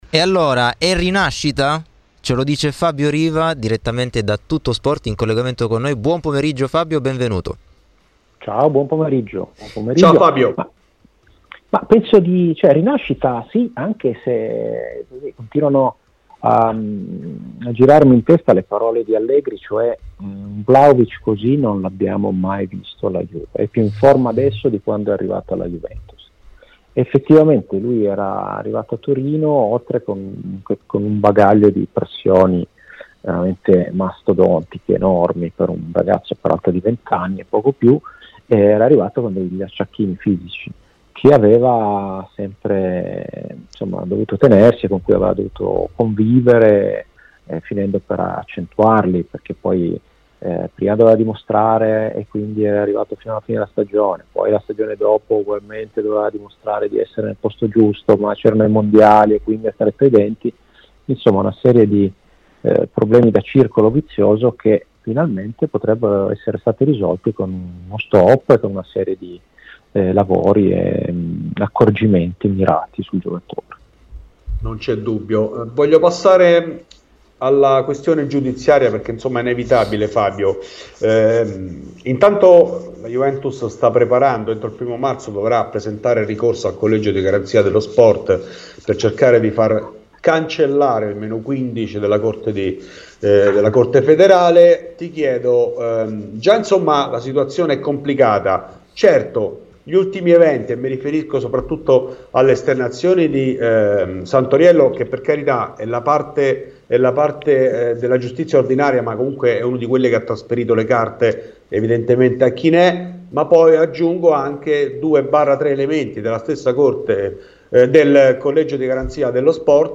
Nel podcast l'intervento integrale